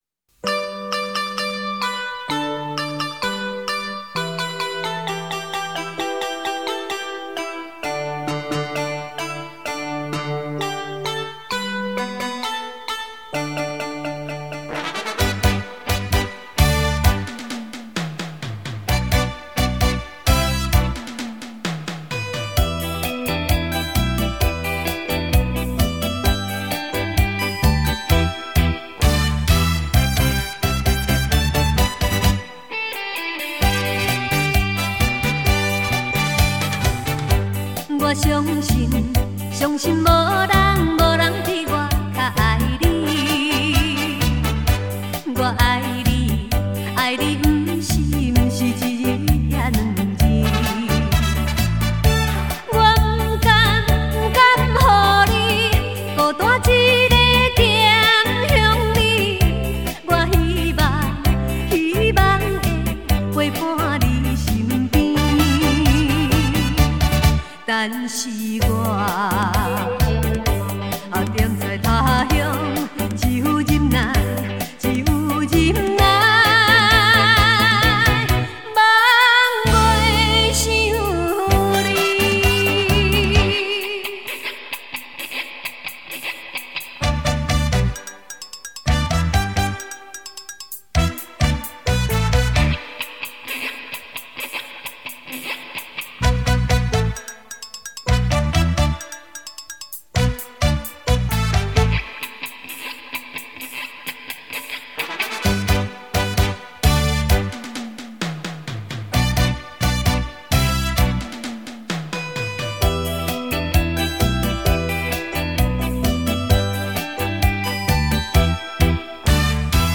舞厅规格歌唱版
舞厅规格
翅仔舞歌唱版